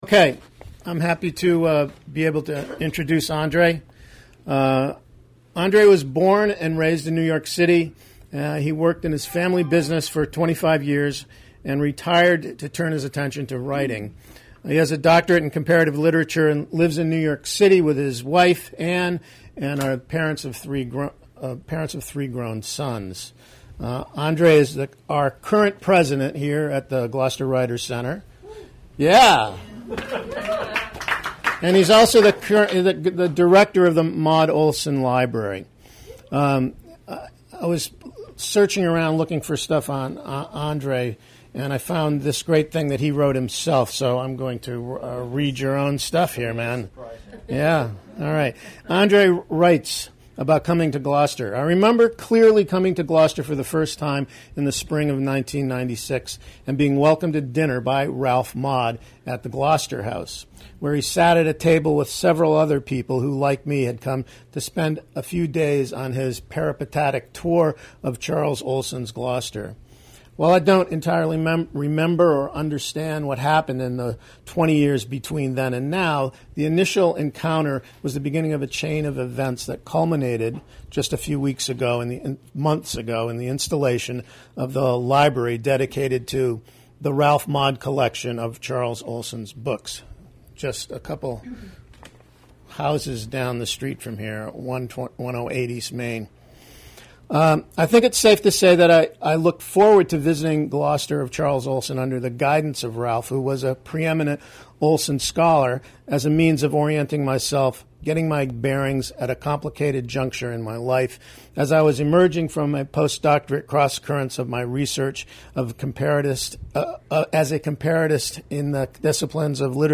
reads at the Gloucester Writers Center